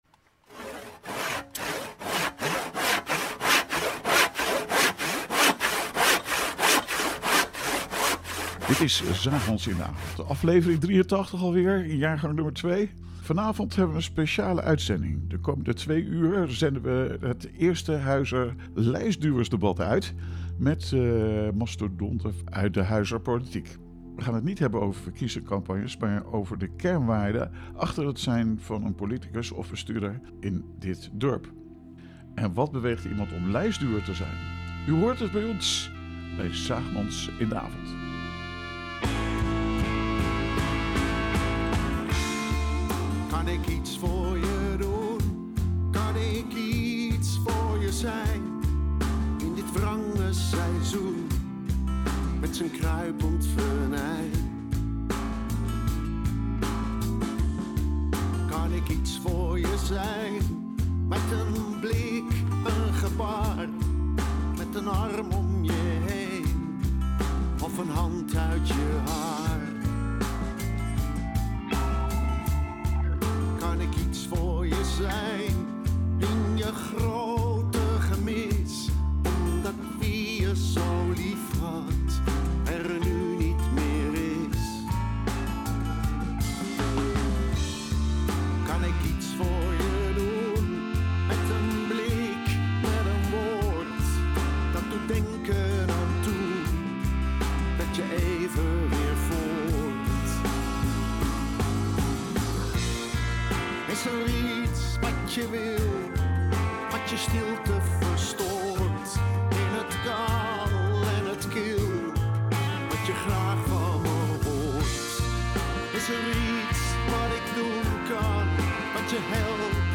Lijstduwers, mannen en vrouwen die een onverkiesbare plaats op de kieslijst innemen.